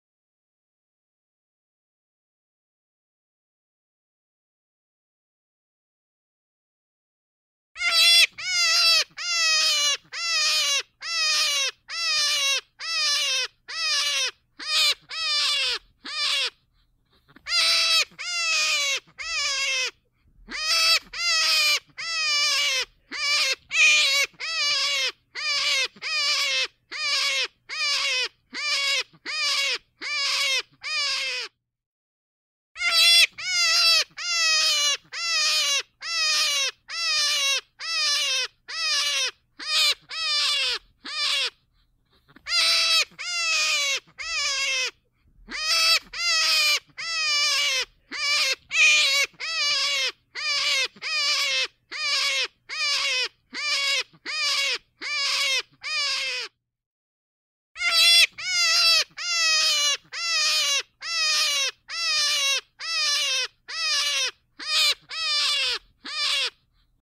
Звук раненого зайца для манка на охоту